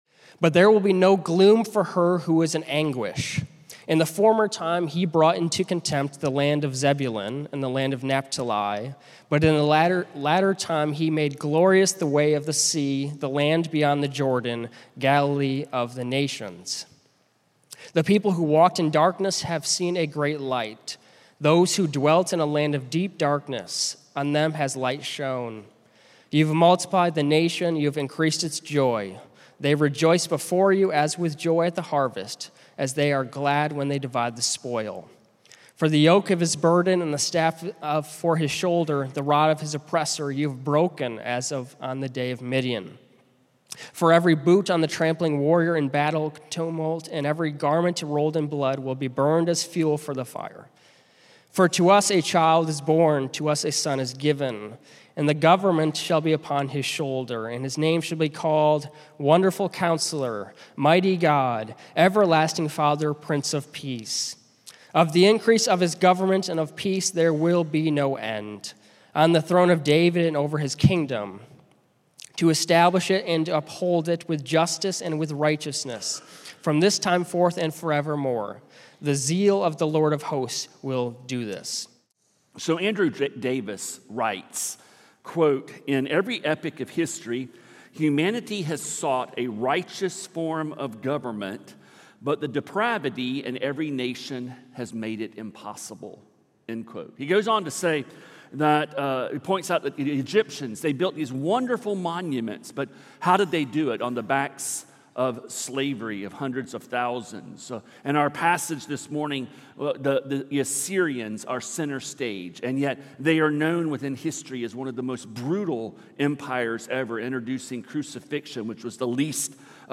A message from the series "Good News of Great Joy."